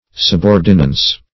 Search Result for " subordinance" : The Collaborative International Dictionary of English v.0.48: Subordinance \Sub*or"di*nance\, Subordinancy \Sub*or"di*nan*cy\, n. [Pref. sub + L. ordinans, p. pr. of ordinare.